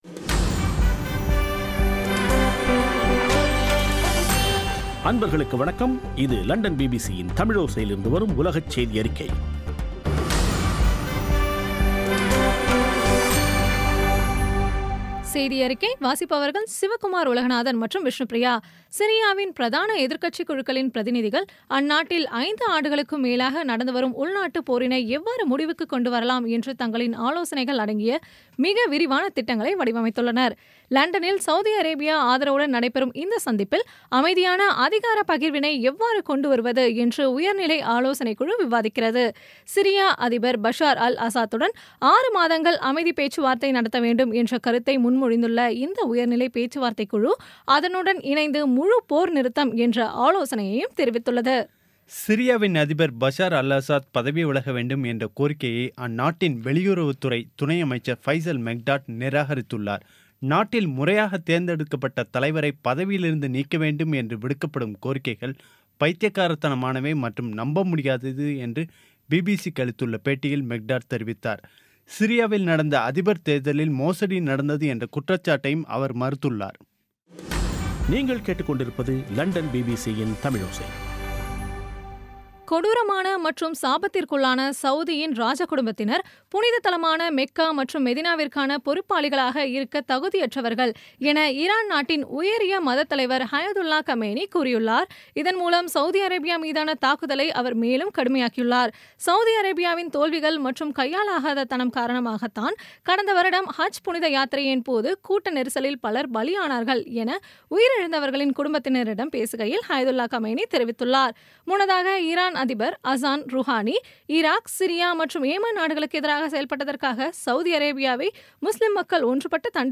இன்றைய (செப்டம்பர் 7-ஆம் தேதி) பிபிசி தமிழோசை செய்தியறிக்கை